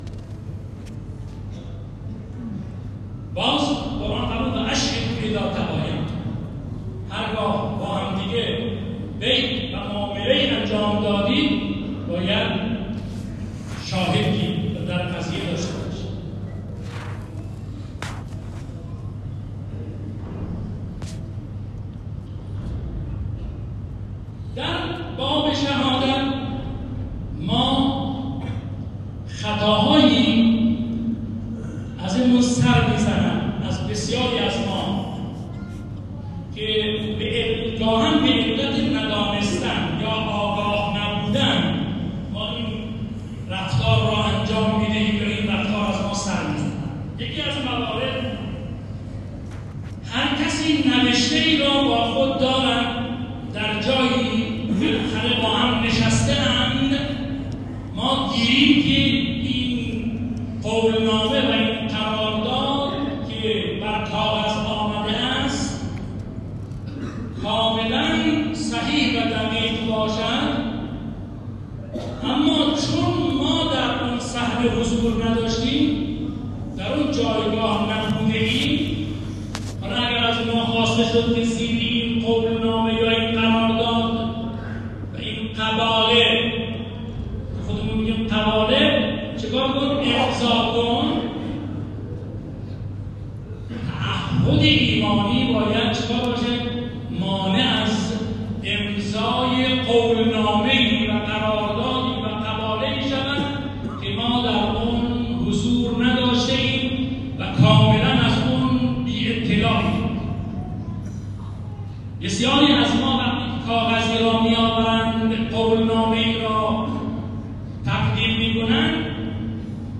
سخنان خطیب نماز جمعه را از طریق فیال های صوتی زیر می توانید بشنوید: